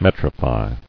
[met·ri·fy]